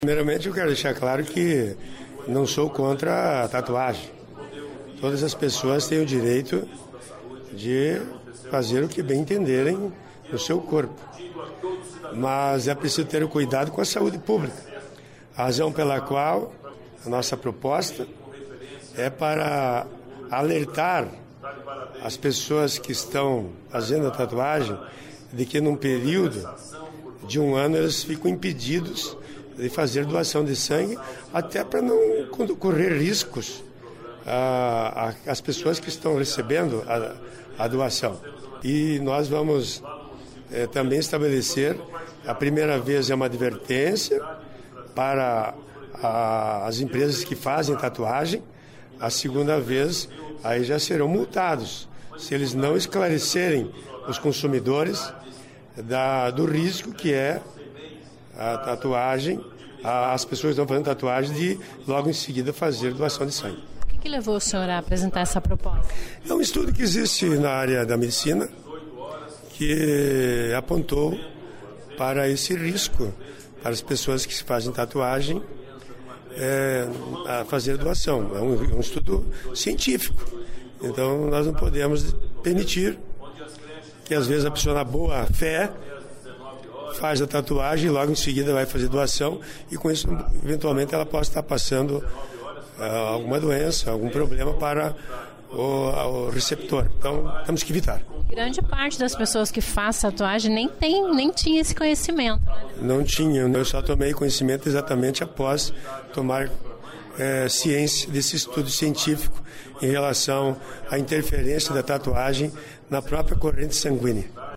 É o que prvê um projeto de lei do deputado nereu Moura (PMDB). Ouça a entrevista com o parlamentar.